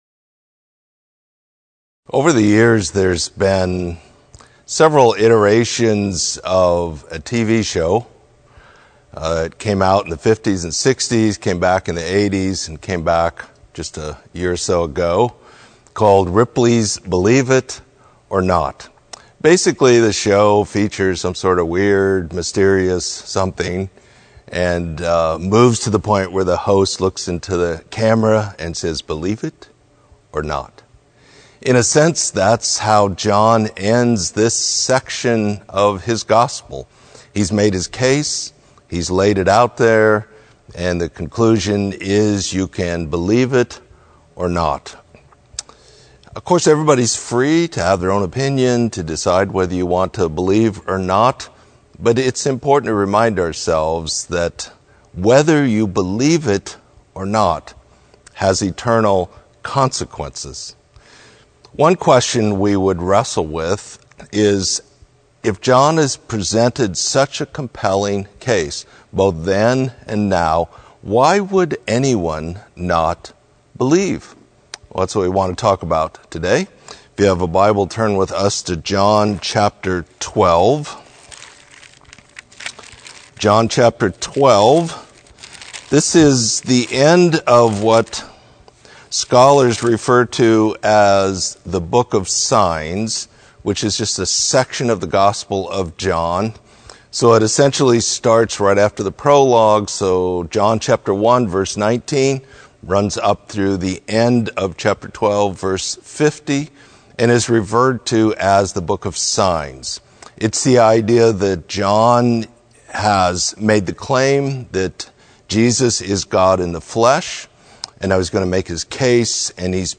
Sermon: Believe it or Not